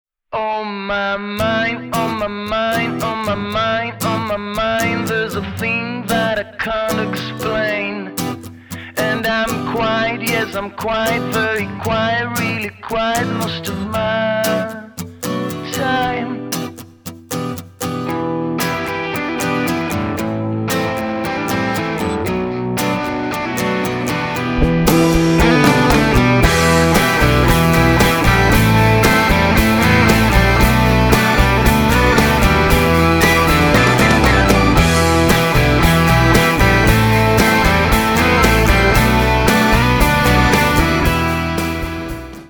гитара
мужской вокал
рок